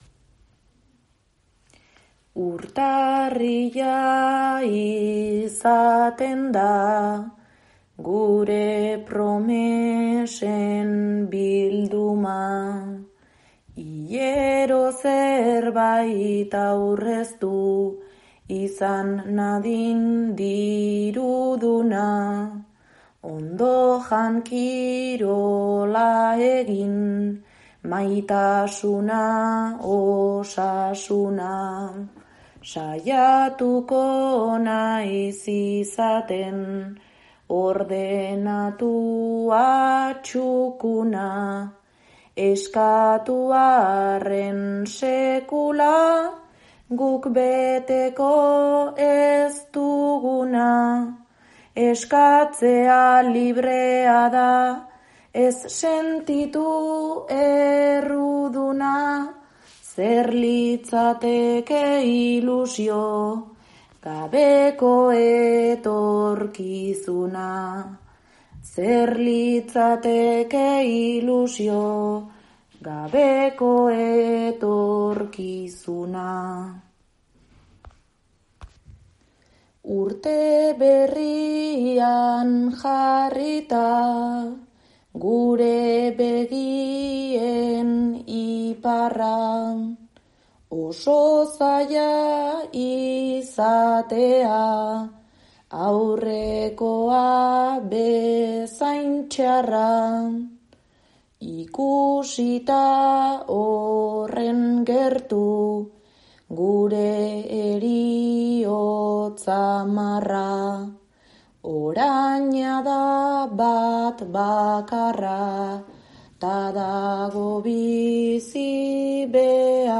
urte berriari eskainitako bertsoekin.